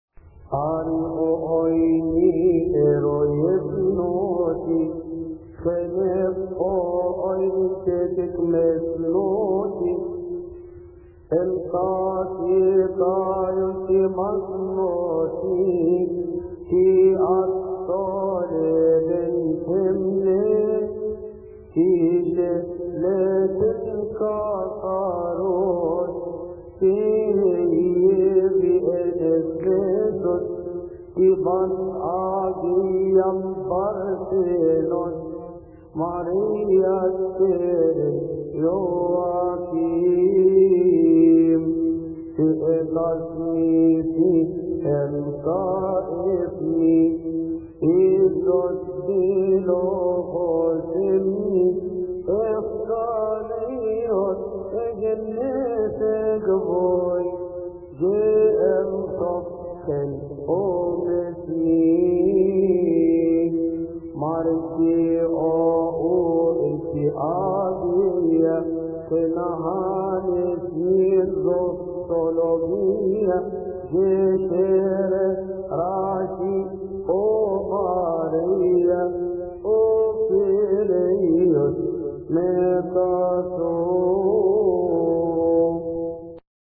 مكتبة الألحان
المرتل
يصلي في تسبحة عشية أحاد شهر كيهك